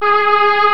STRINGS  1.1.wav